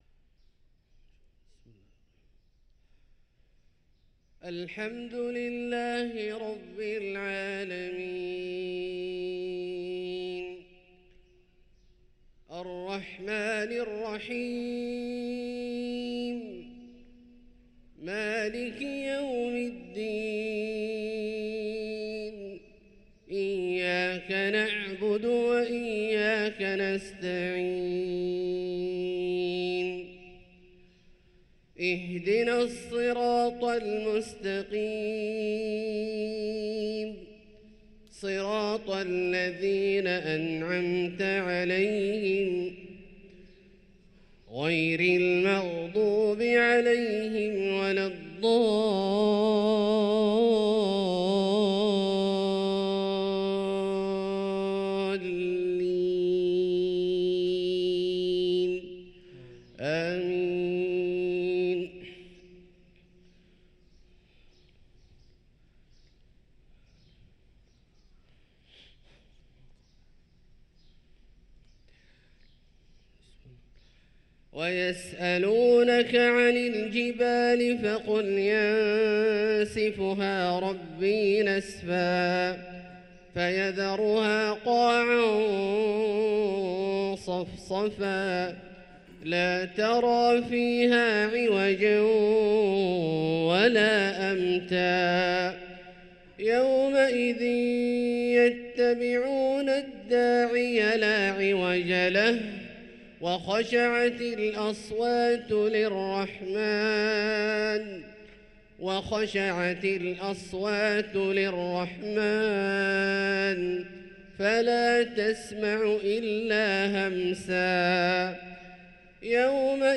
صلاة الفجر للقارئ عبدالله الجهني 5 جمادي الأول 1445 هـ
تِلَاوَات الْحَرَمَيْن .